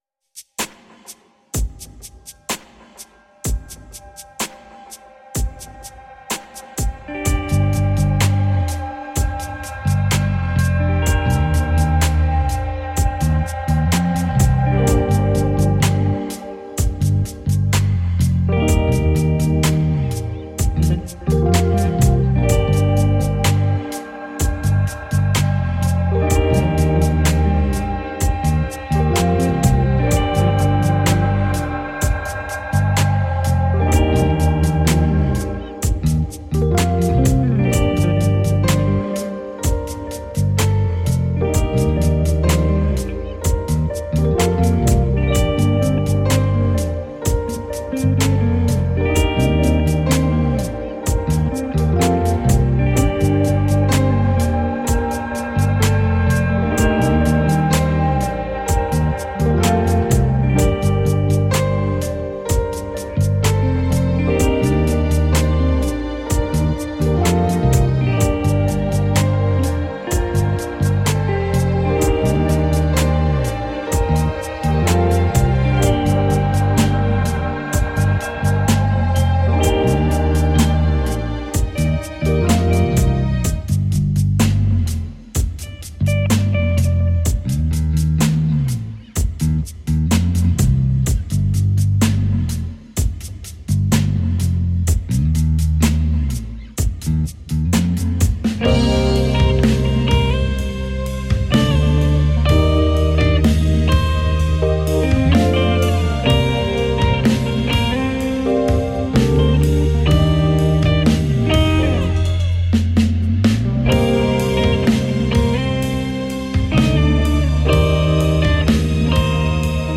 Atmospheric, instrumental soundtracks for daydreaming.
Tagged as: New Age, Instrumental New Age, Cinematic